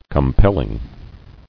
[com·pel·ling]